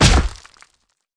SFX击中身体喷血2音效下载
SFX音效